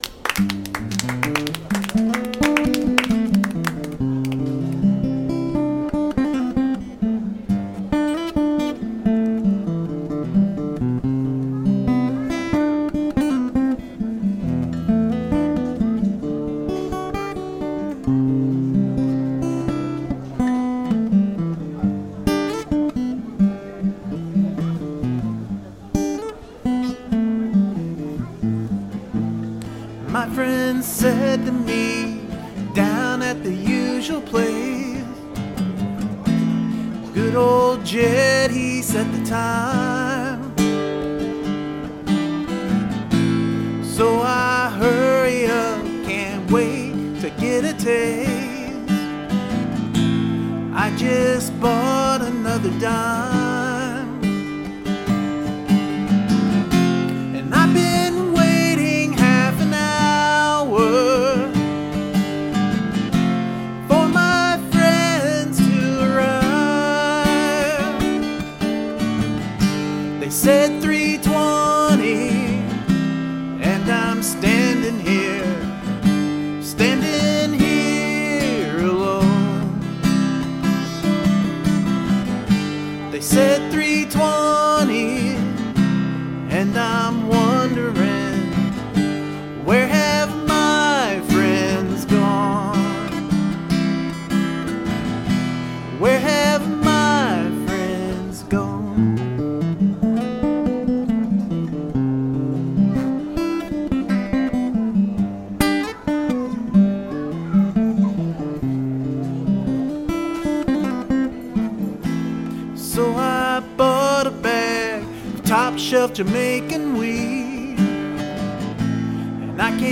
Ambience